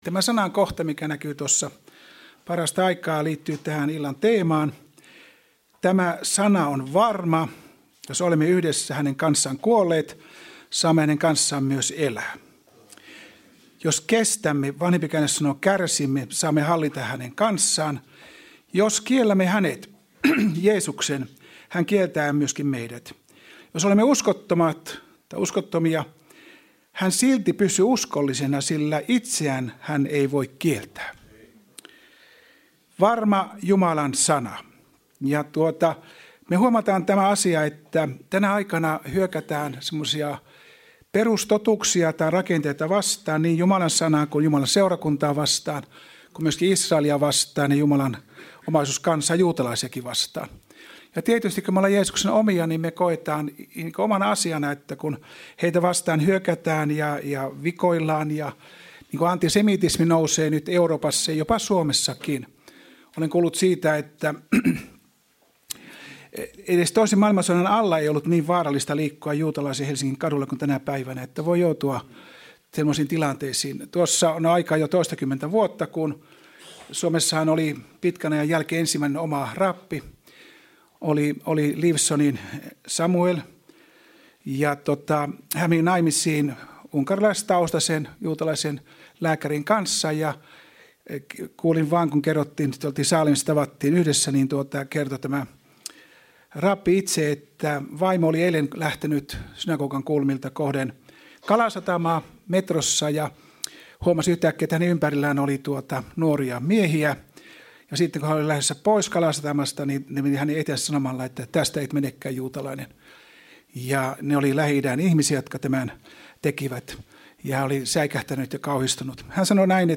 Service Type: Raamattutunti